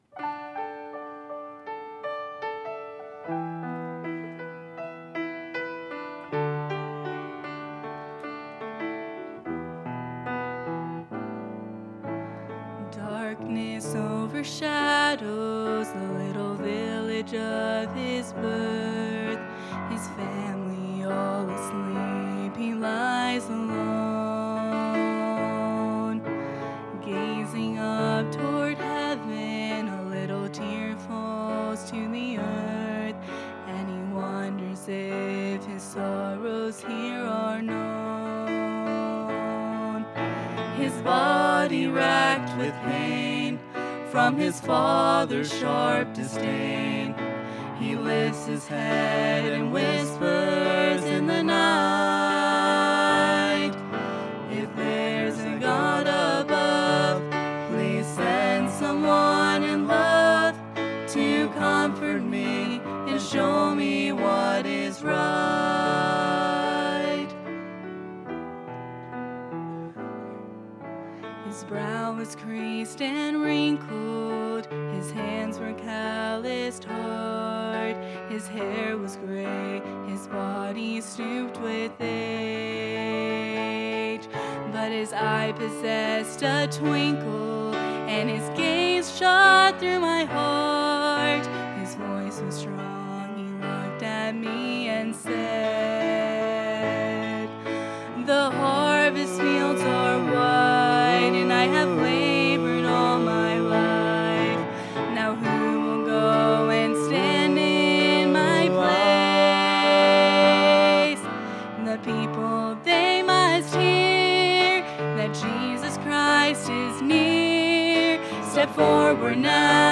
Satisfying God | Sunday PM